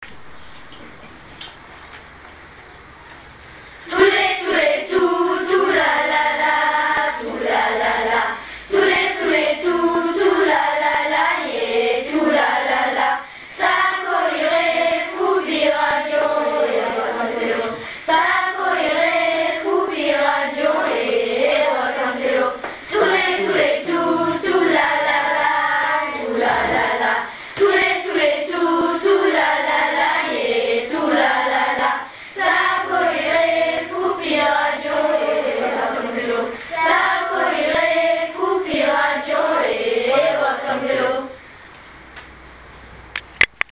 Chant burkinab�